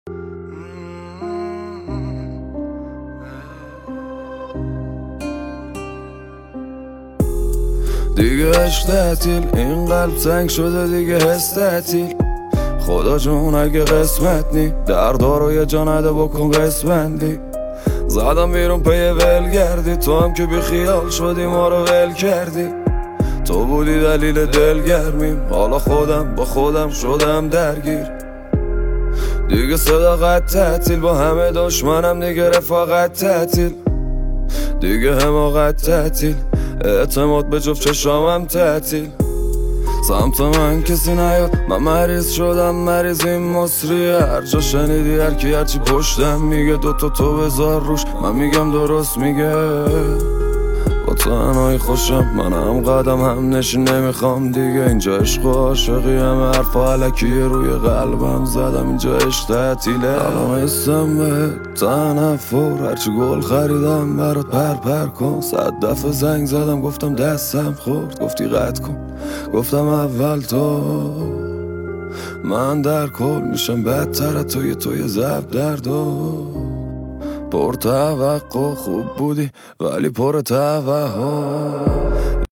(دلی)